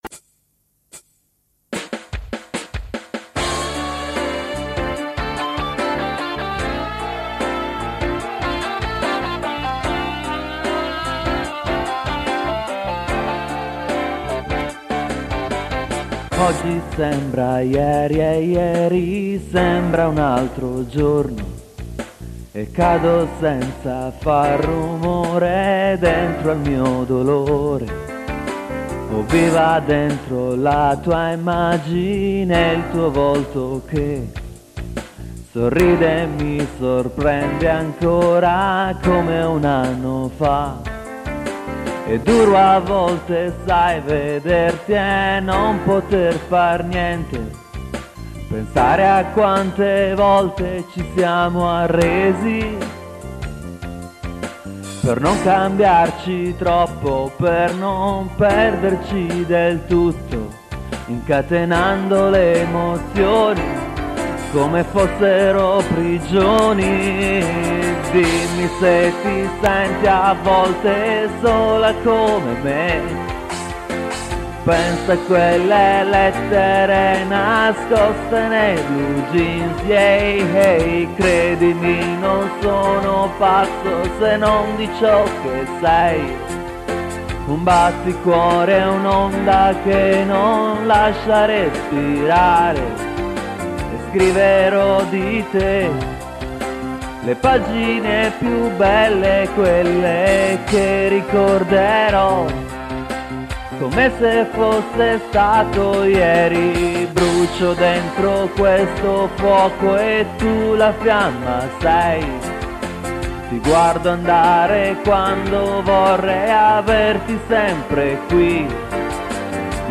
• Multitrack Recorder Zoom MRS-4